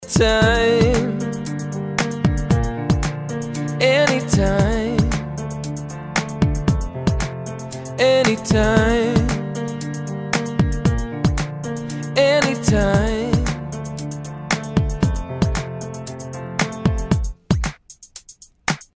Вот ваш щедевр без голоса.
Второй аккорд в последовательности, чистое Си бемоль мажорное трезвучие.
Нота Фа отчётлива слышна там.